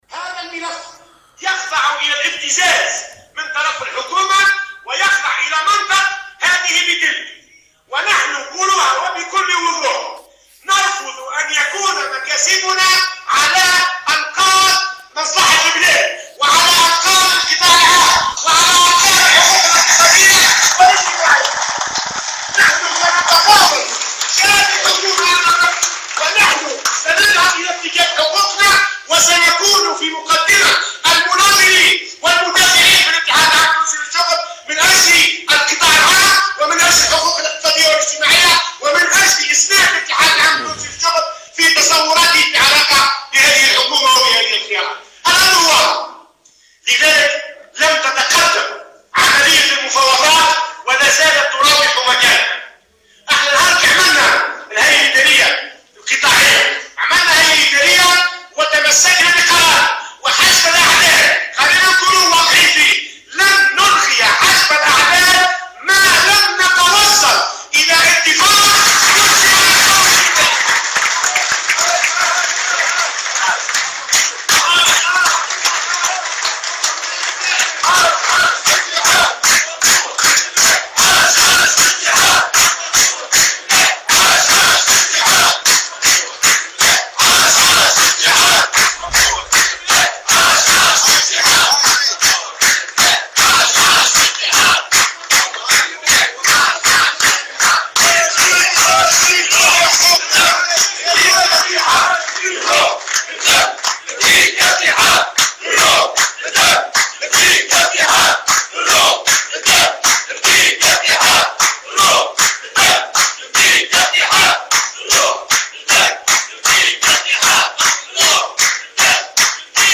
وقال في كلمة ألقاها في ندوة جهوية لإطارات قطاع التعليم الثانوي بدار الاتحاد الجهوي للشغل بصفاقس صباح اليوم، إن عملية التفاوض لم تتقدم وتراوح مكانها، مشيرا إلى أن المطلوب من الحكومة أن تكون المفاوضات سريعة وجدية، مشددا على تمسّك الجامعة بقرار حجب الأعداد في حال عدم الوصول إلى اتفاق.